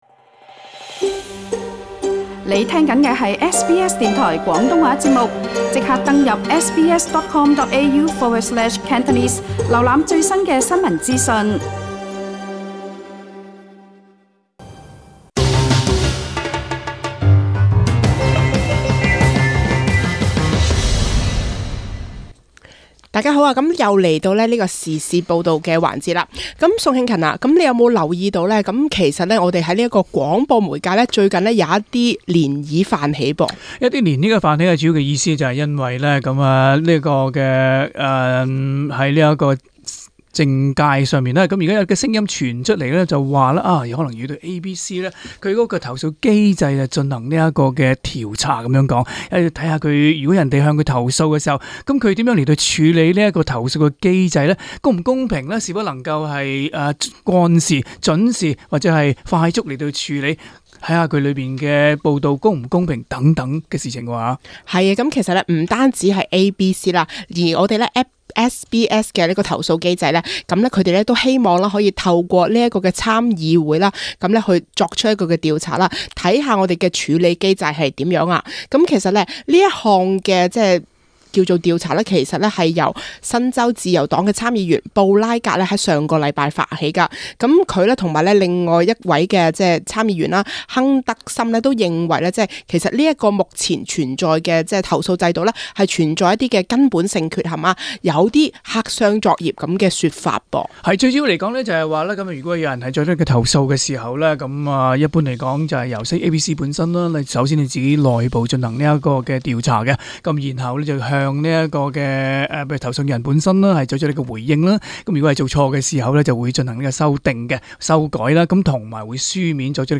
時事報導